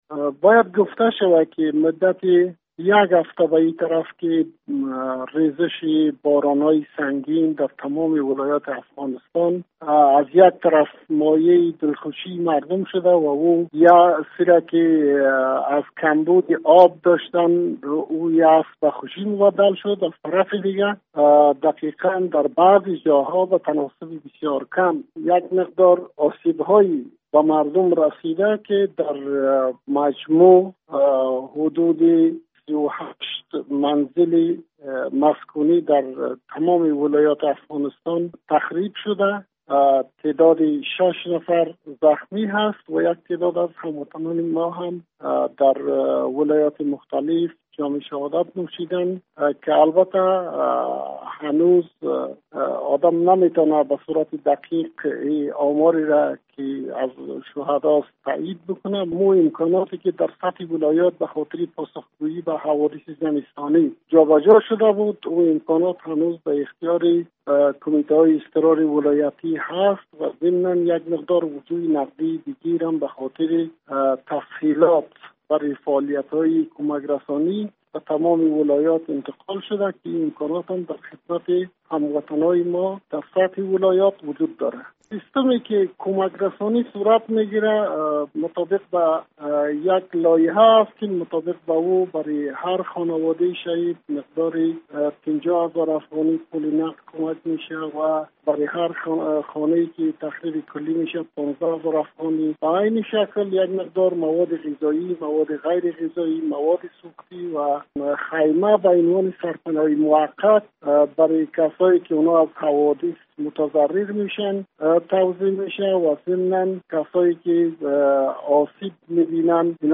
مصاحبه - صدا
مصاحبه با اسلم سیاس معاون اداره مبارزه با حوادث طبیعی افغانستان: